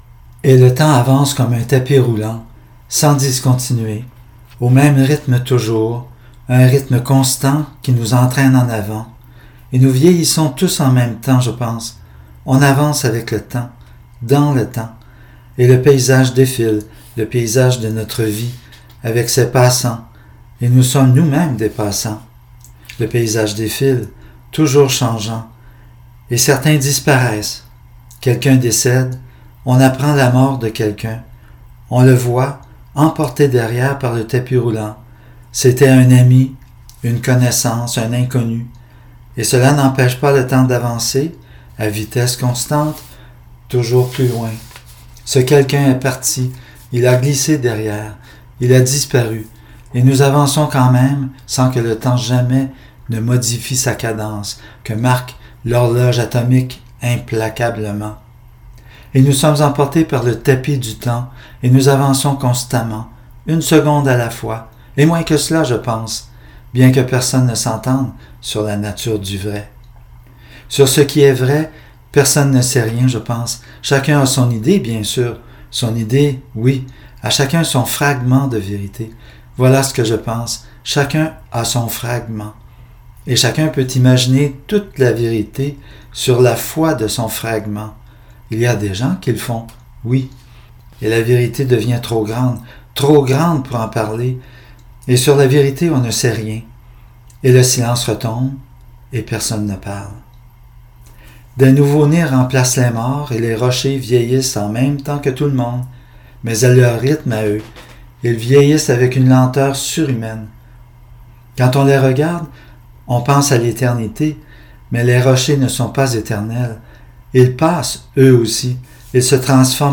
Enregistrement audio (texte lu par l’auteur)